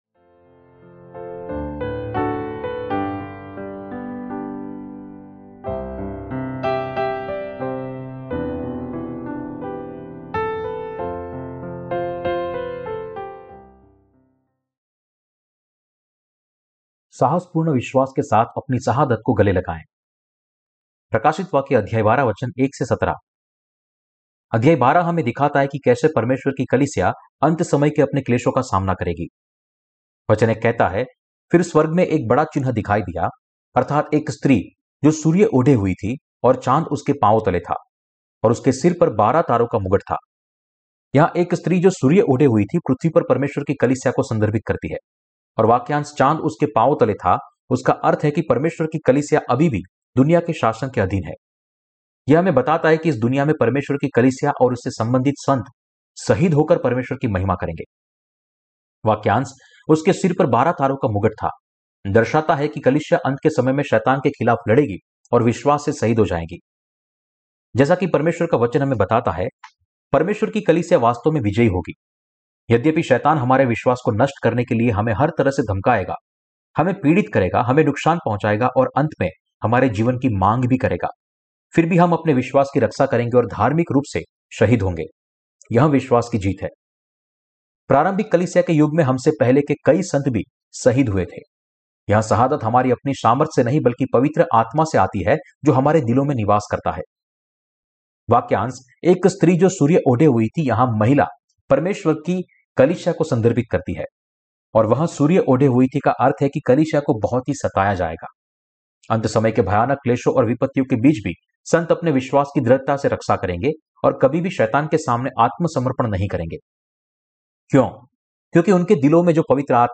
प्रकाशितवाक्य की किताब पर टिप्पणी और उपदेश - क्या मसीह विरोधी, शहादत, रेप्चर और हजार साल के राज्य का समय नज़दीक है?